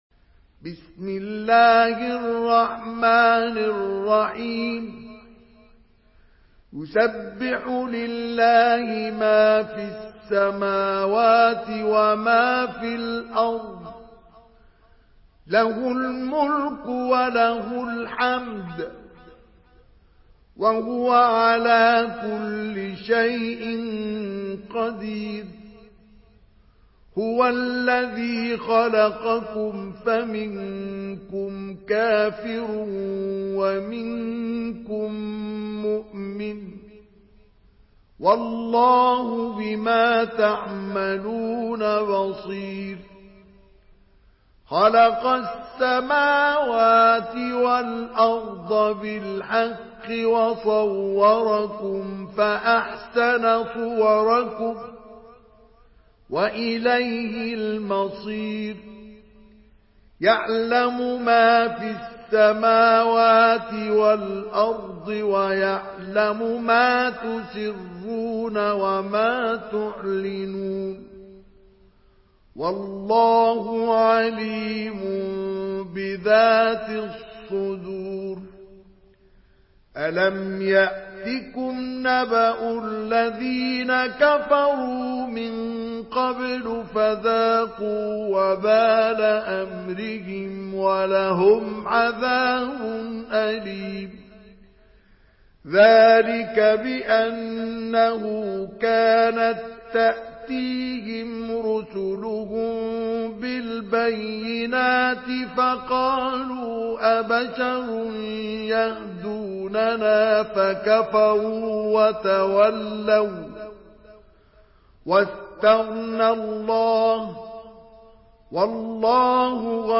سورة التغابن MP3 بصوت مصطفى إسماعيل برواية حفص
مرتل